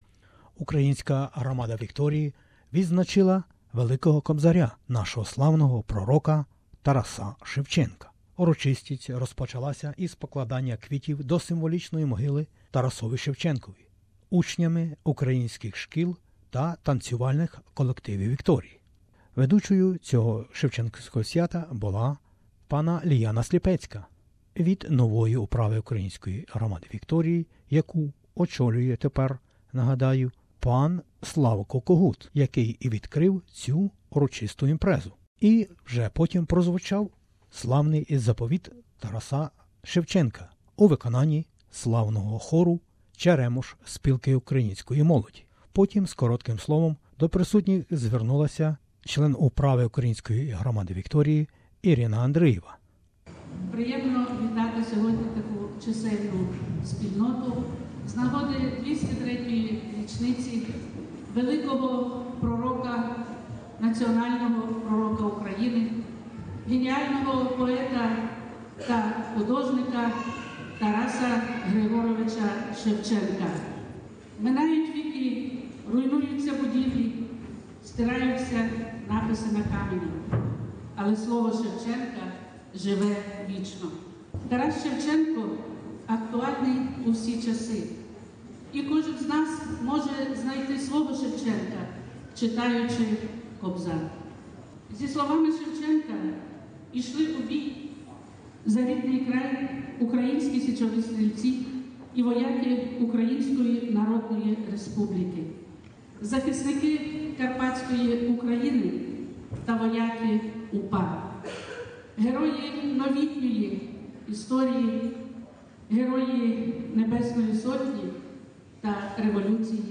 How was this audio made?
On Sunday, March 19, Ukrainians in Victoria dedicated to the memory of the great Ukrainian poet and patriot, painter and educator Taras Shevchenko was held at the Ukrainian House in Essendon.